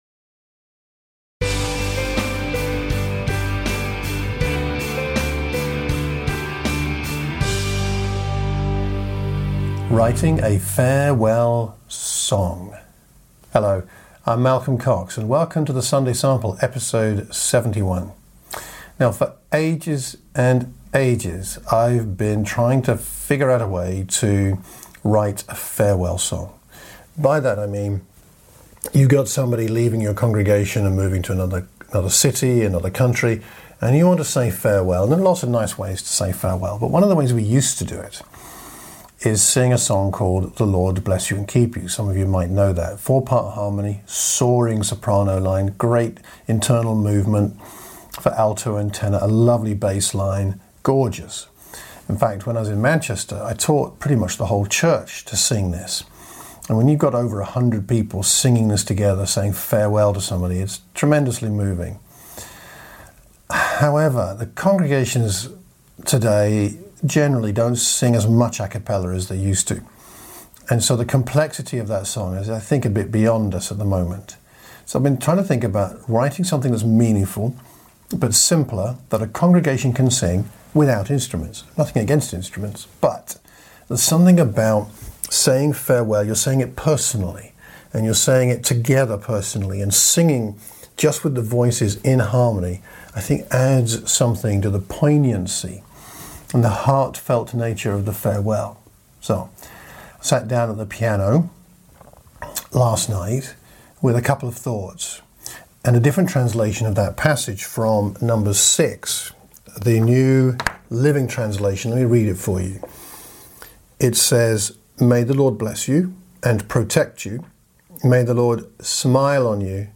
I have been working on writing a congregational voices only “farewell” song for a long time.
Something came to me last night at the piano.